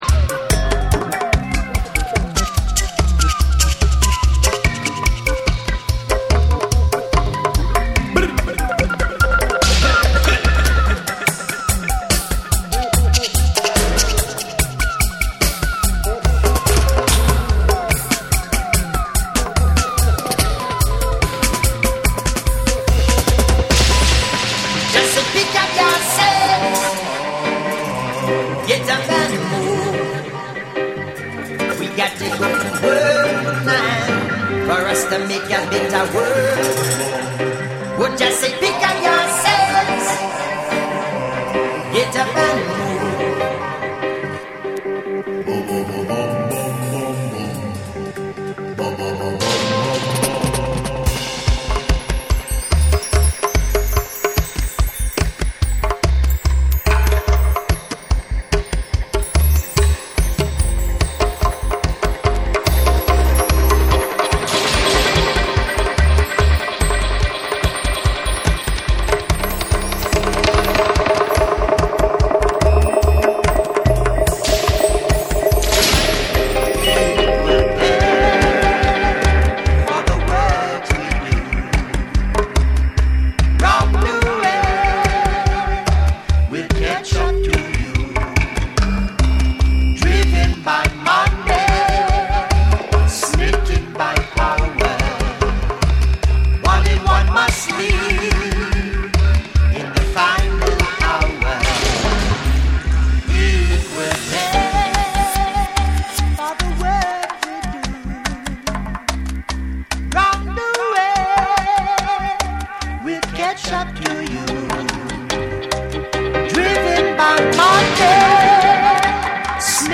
ジャマイカのルーツ・レゲエとフランスのダブ・サウンドの融合によって生み出された、深遠かつスピリチュアルな作品！